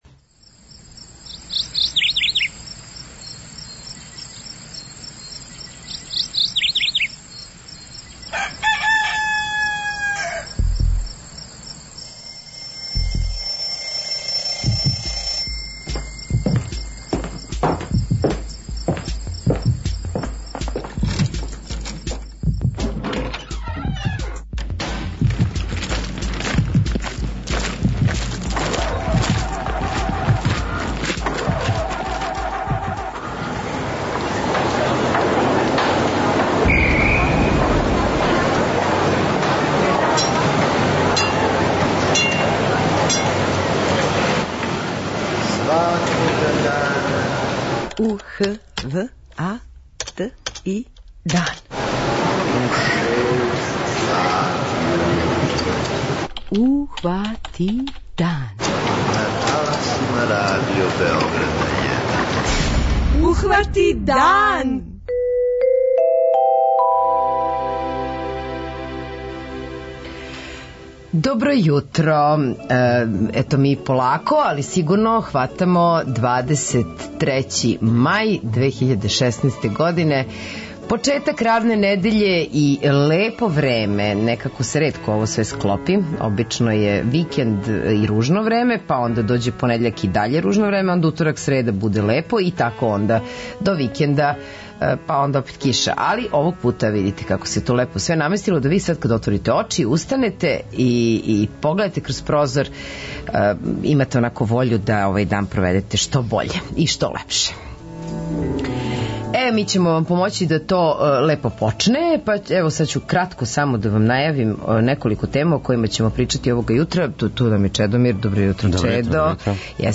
Данас је међународни дан опомене на независност правосуђа. Гошћа у студију је бивша председница Врховног суда Србије Вида Петровић Шкеро.